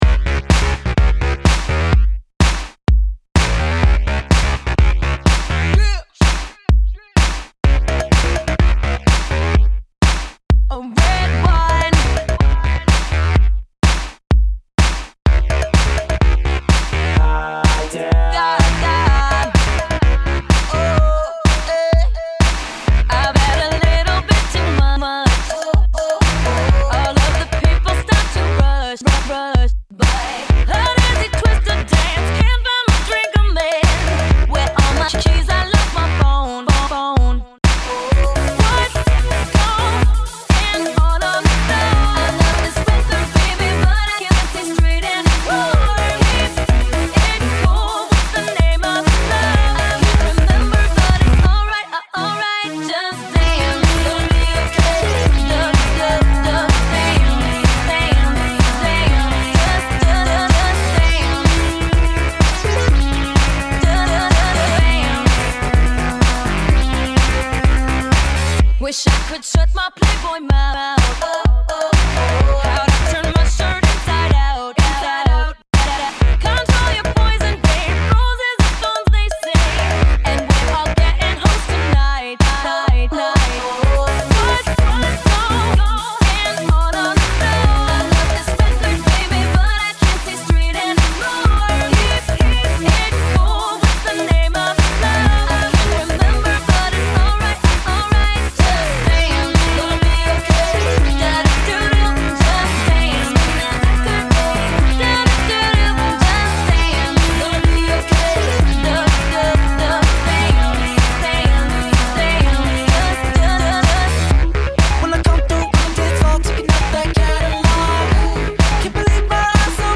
Los Angeles based electro duo